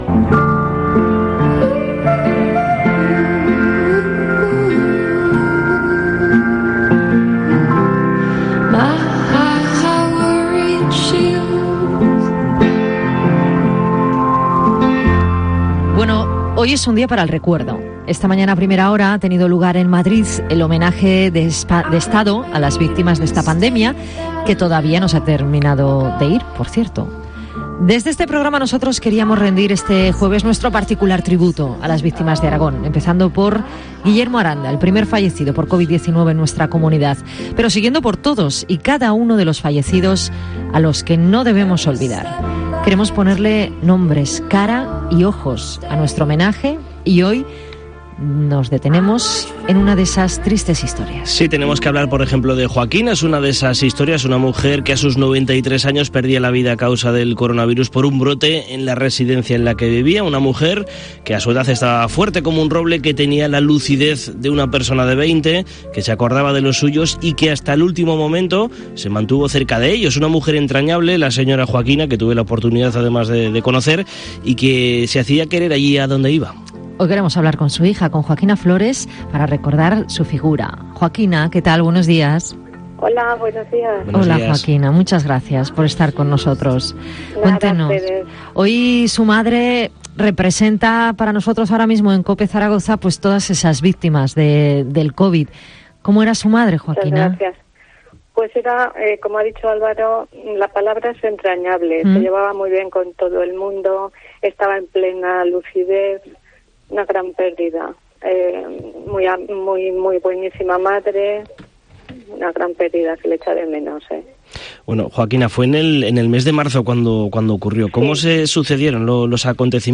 El testimonio de una mujer que ha perdido a su madre durante la pandemia del coronavirus y que también ha pasado el COVID-19